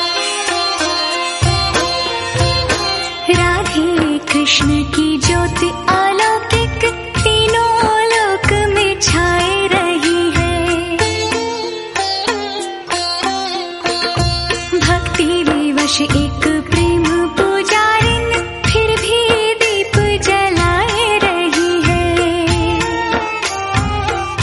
Devotional Ringtones Female Version Ringtones